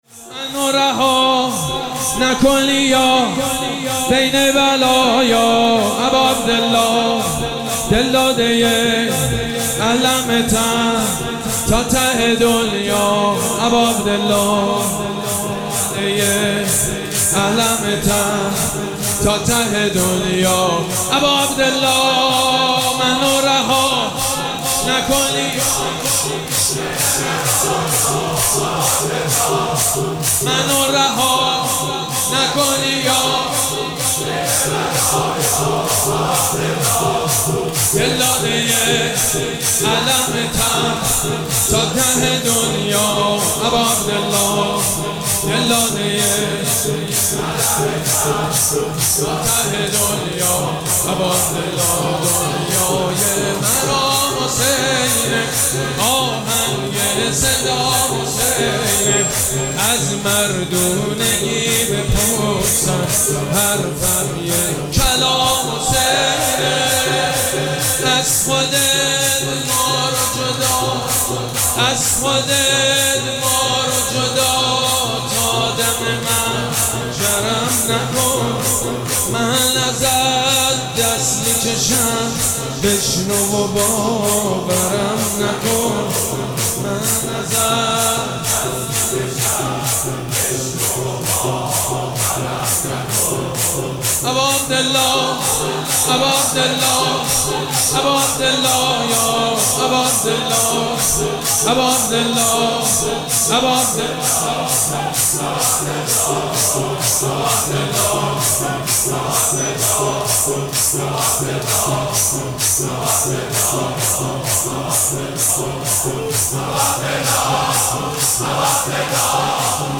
مراسم عزاداری شب اول محرم الحرام ۱۴۴۷
شور
مداح
حاج سید مجید بنی فاطمه